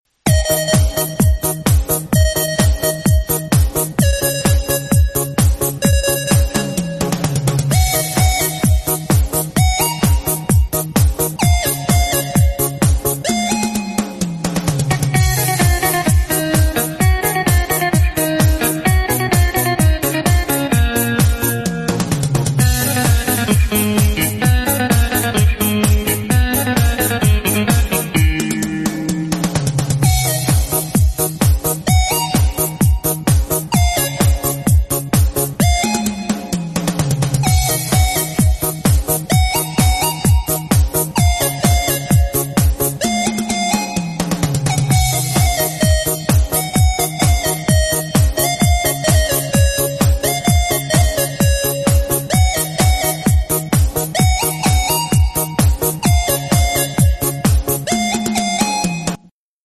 instrumentalka